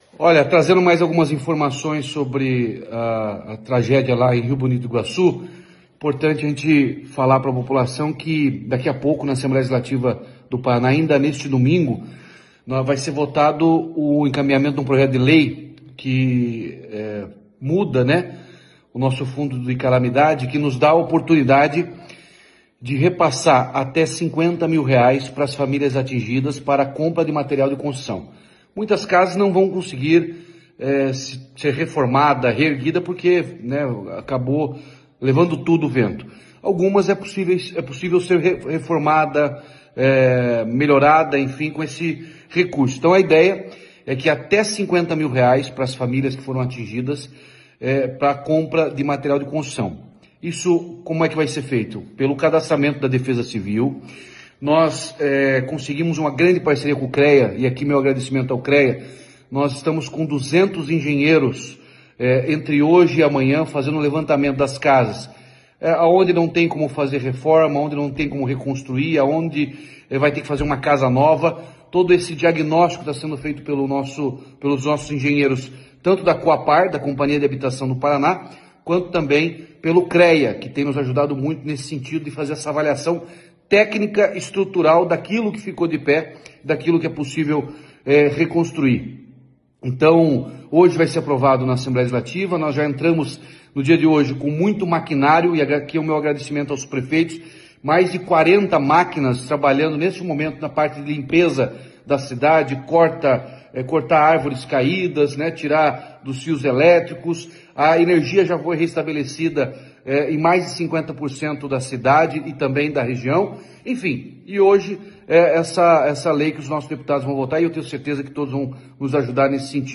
Sonora do governador Ratinho Junior sobre preparação do Estado para reconstruir casas, Apae e escolas de Rio Bonito do Iguaçu